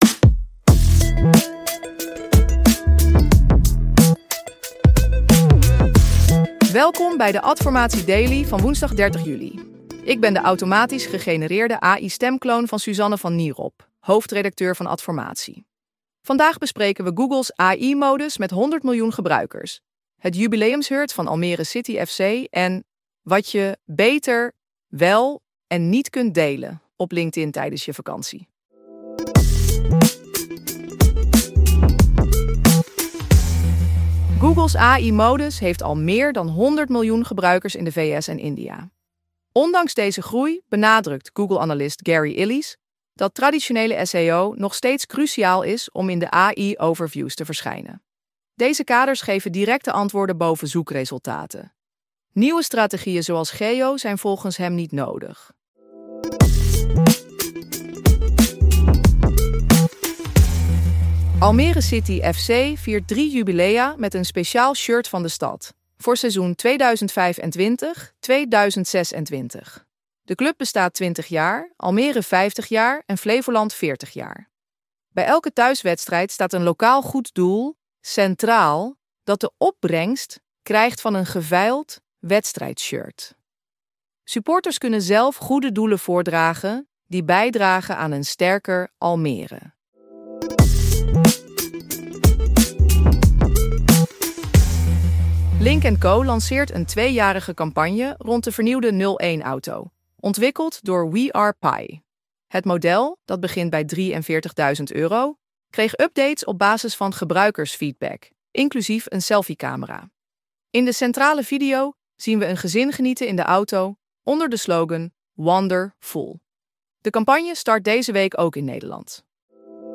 Geïnteresseerd in een automatisch gegenereerde podcast voor jouw content?